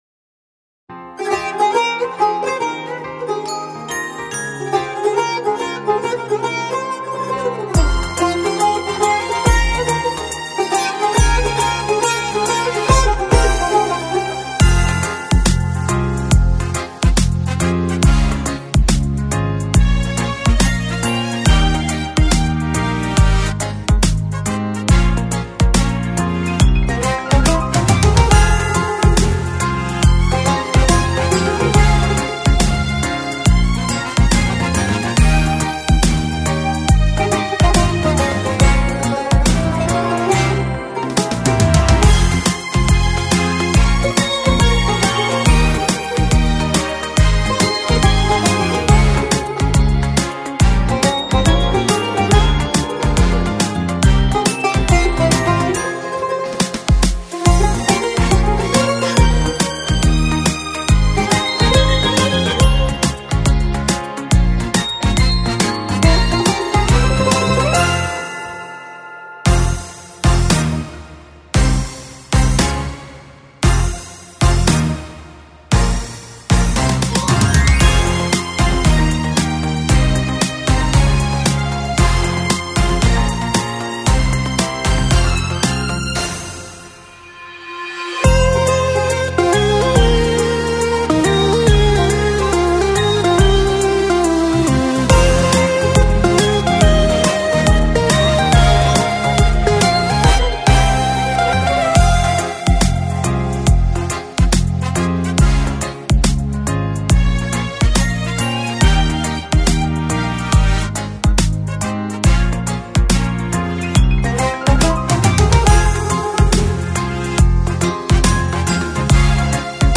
سرودهای دانش آموزی
بی‌کلام